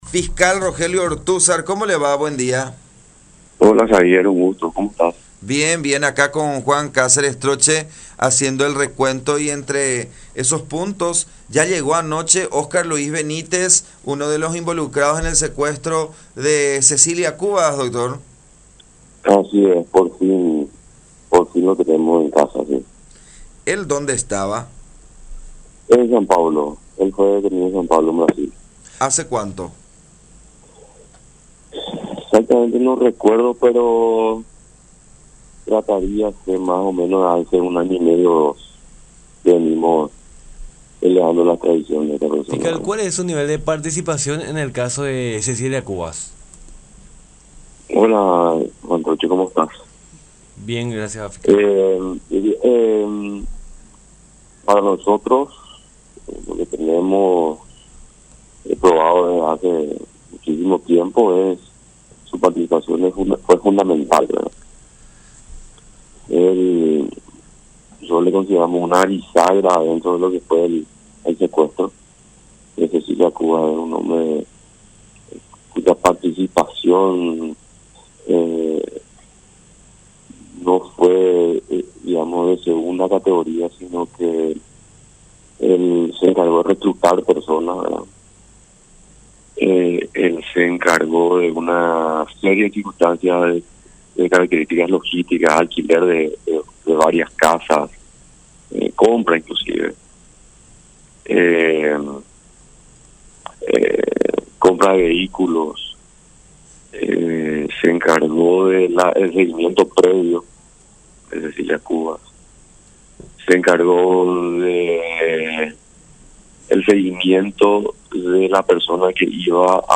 “Por fin lo tenemos en Paraguay, después de un largo tiempo”, manifestó el fiscal Rogelio Ortúzar, uno de los investigadores del caso, en comunicación con La Unión, comentando que el proceso de extradición duró cerca de un año y medio.